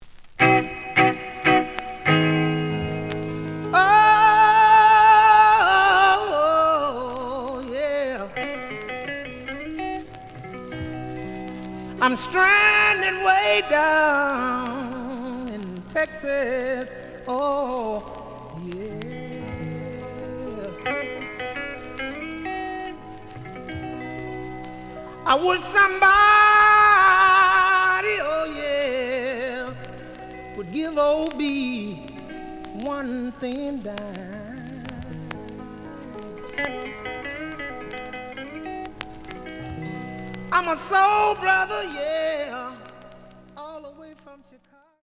The audio sample is recorded from the actual item.
●Genre: Blues
Looks good, but slight noise on both sides.